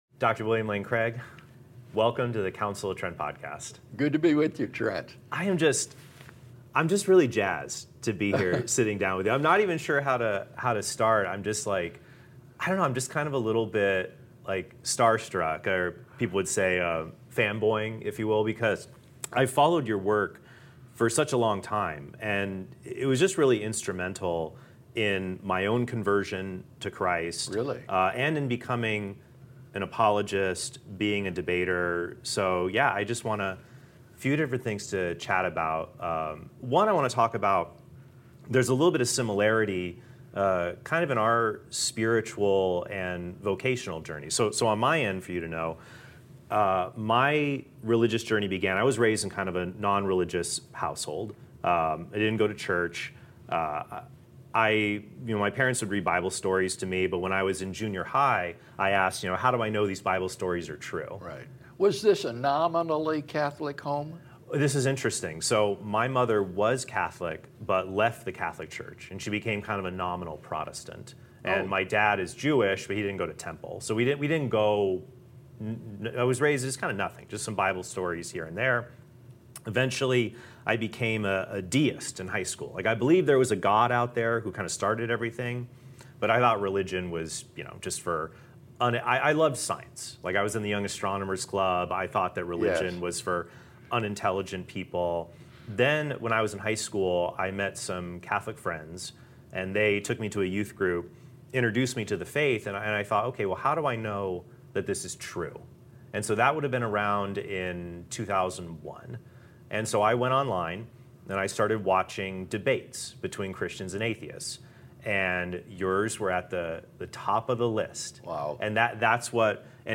I had a friendly chat with William Lane Craig